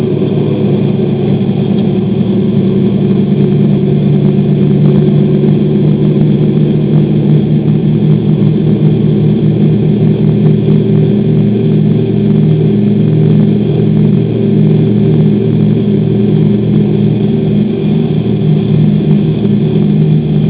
DC-3 Sound Files
Yet another take-off (recorded from inside the passenenger cabin).